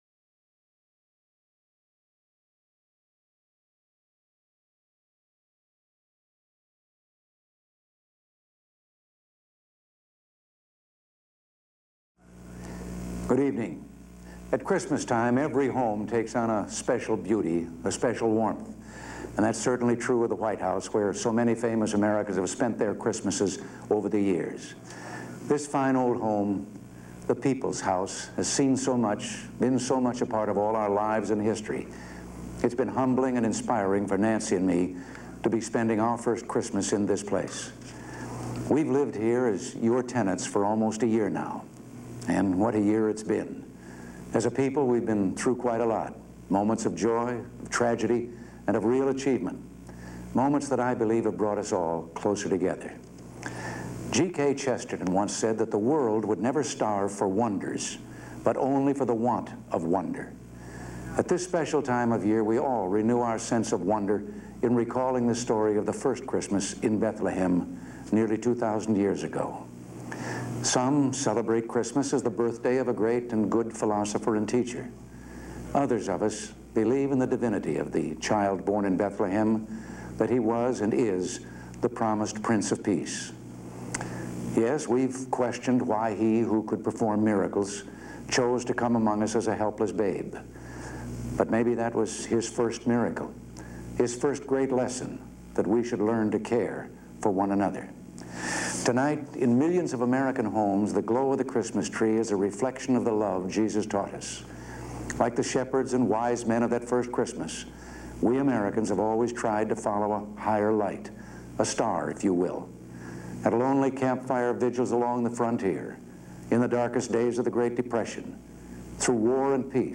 December 23, 1981: Address to the Nation on Christmas and the Situation in Poland